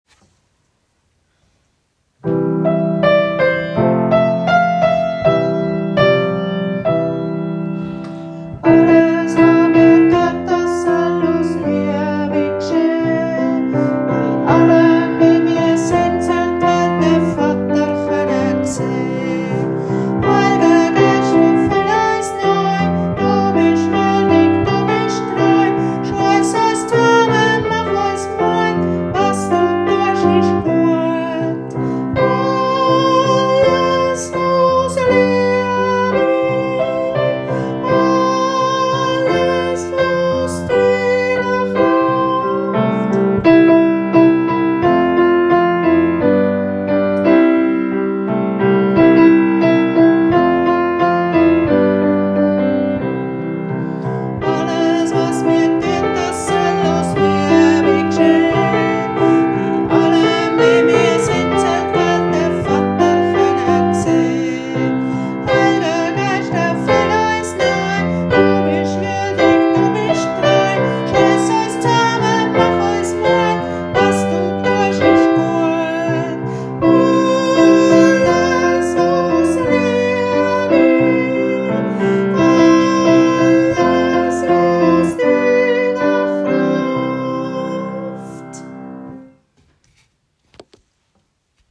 Kanon «Alles us Liebi»